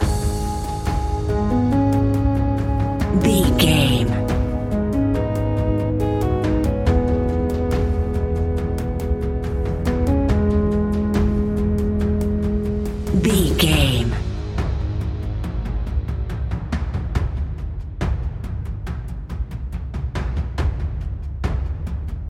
In-crescendo
Aeolian/Minor
G#
ominous
dark
eerie
synthesiser
drums
horror music
Horror Pads